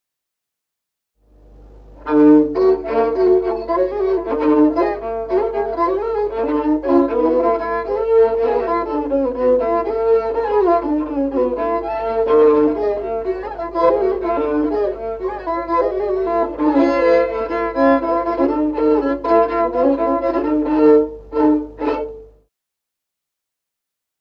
Музыкальный фольклор села Мишковка «Зоречка», партия 2-й скрипки.